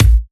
• Sharp Bass Drum One Shot A Key 567.wav
Royality free kick sound tuned to the A note. Loudest frequency: 662Hz
sharp-bass-drum-one-shot-a-key-567-B53.wav